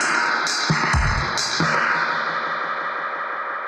Index of /musicradar/dub-designer-samples/130bpm/Beats
DD_BeatFXA_130-01.wav